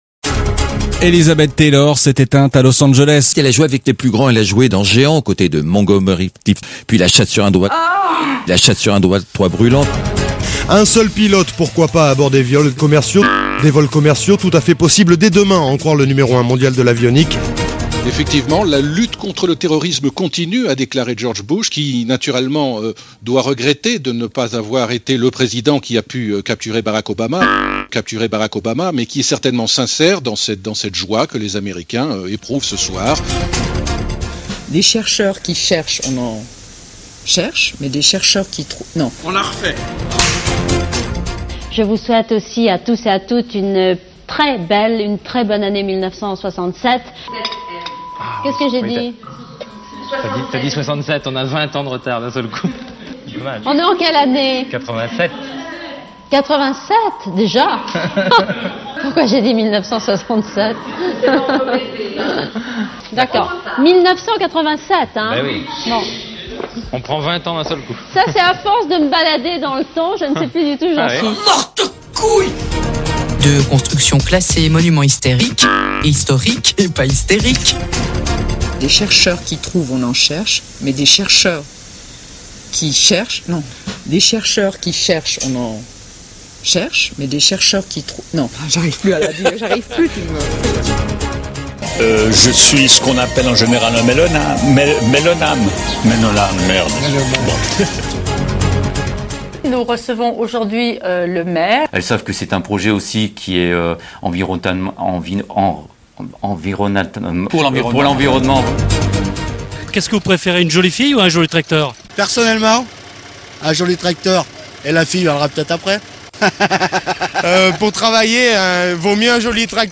Bafouillages et lapsus provenant notamment de radios et TV locales.
12. Bêtisier 2009 RDI (Radio Canadienne) : Bafouillage sur le mot "homéopathes"